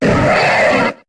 Cri de Drackhaus dans Pokémon HOME.